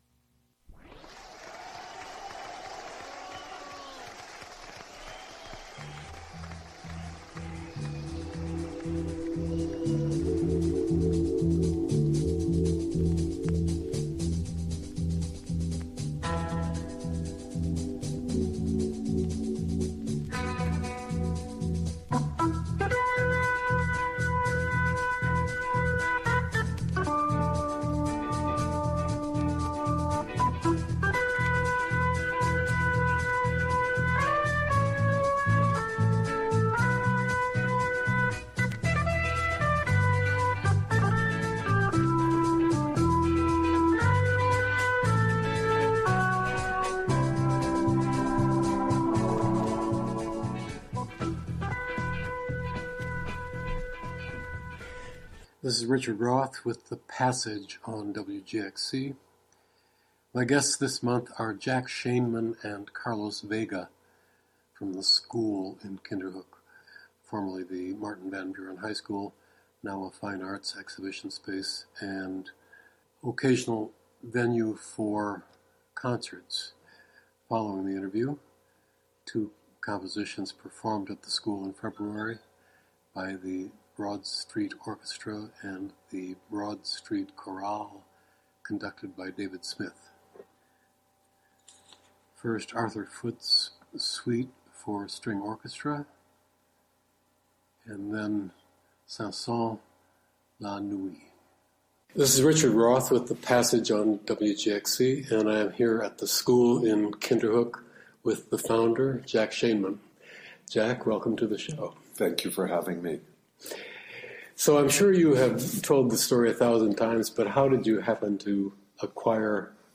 Interview
With music by Arthur Foote and Camille Saint-Saens.